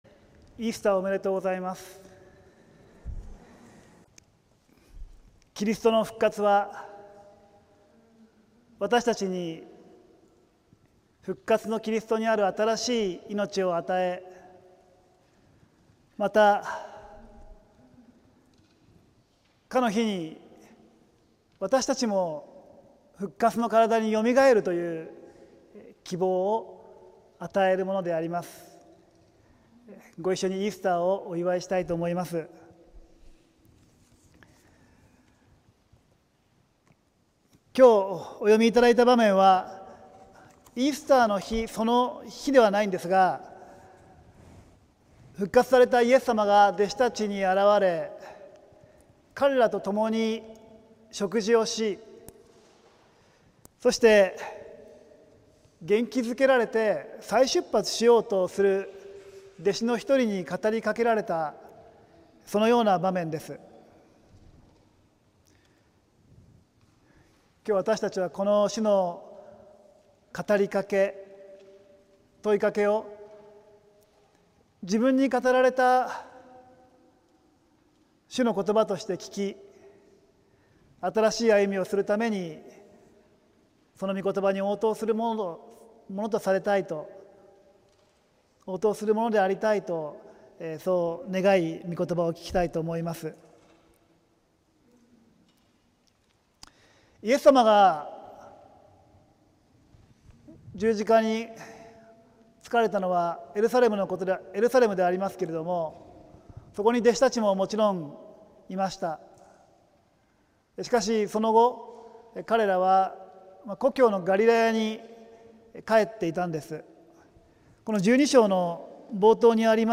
浦和福音自由教会(さいたま市浦和区)の聖日礼拝(2025年4月20日)「あなたはわたしを愛しますか」(週報とライブ/動画/音声配信)